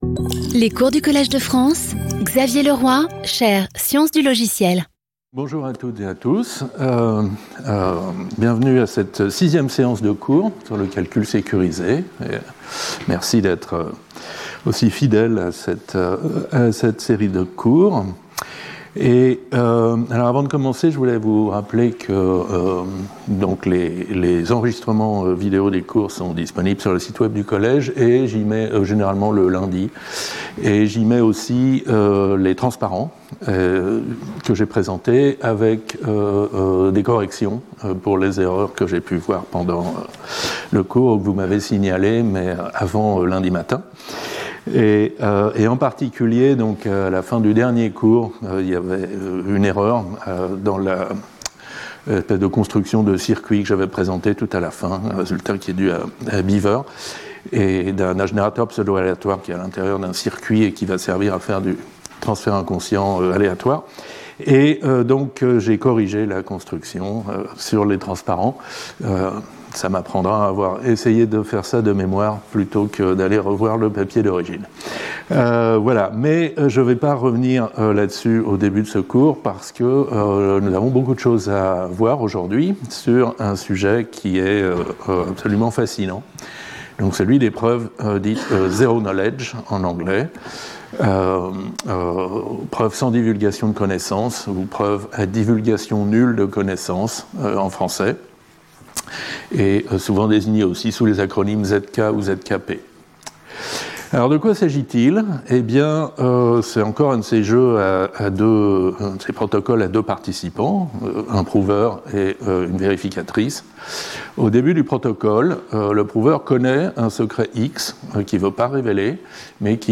Intervenant(s) Xavier Leroy Professeur du Collège de France Événements Précédent Cours 06 Nov 2025 09:30 à 11:00 Xavier Leroy Sécuriser le calcul : introduction et étude de cas Cours 13 Nov 2025 09:30 à 11:00 Xavier Leroy Chiff